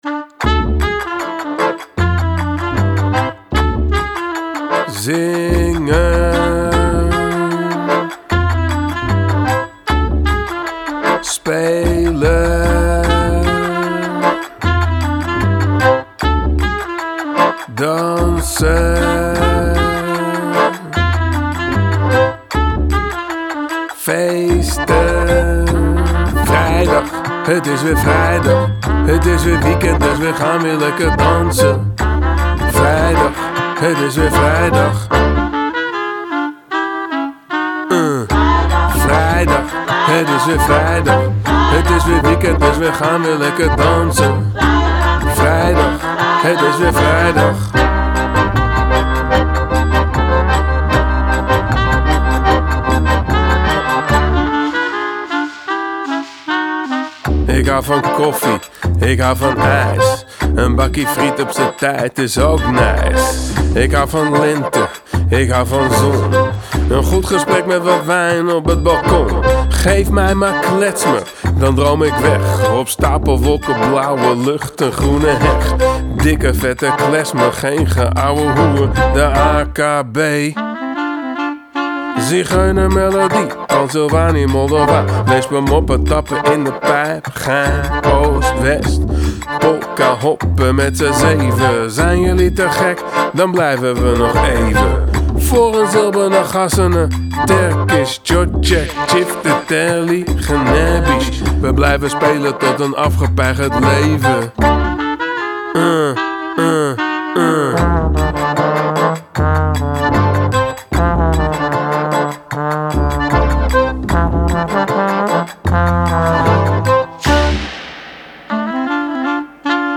Genre: Klezmer, Balkan, Gypsy Jazz, Worldbeat